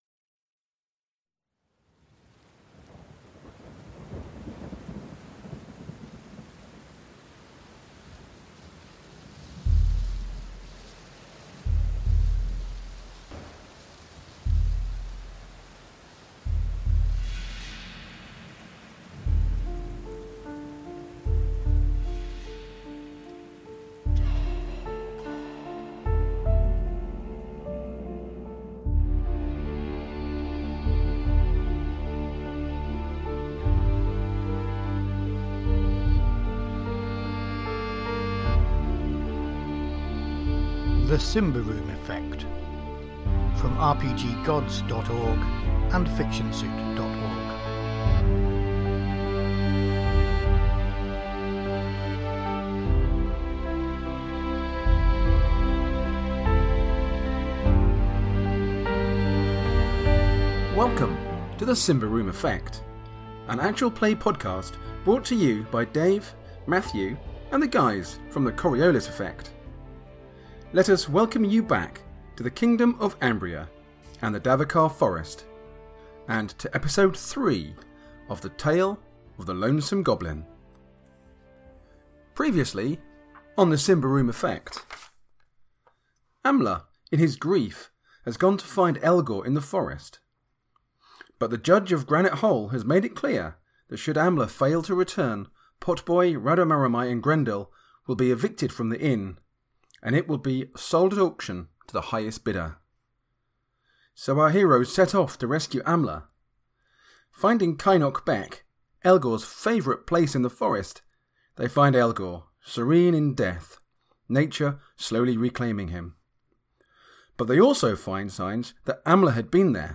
Actual Play